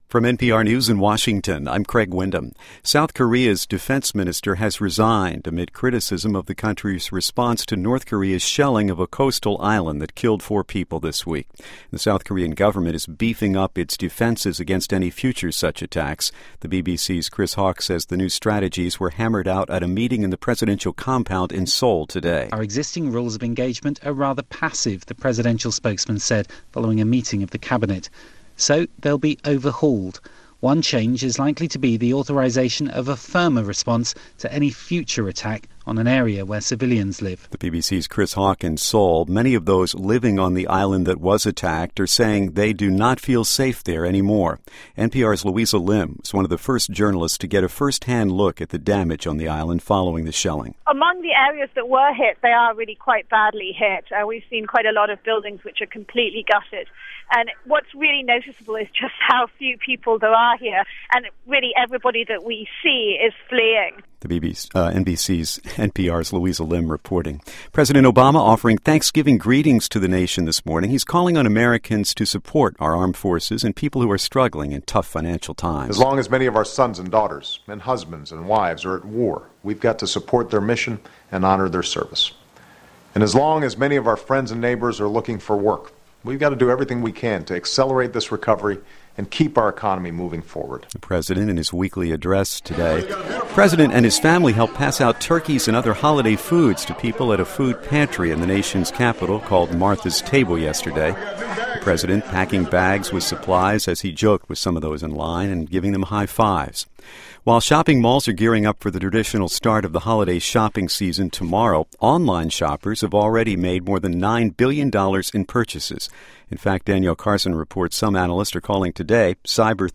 NPR Hourly Newscast: "A brief update of the top news of the hour"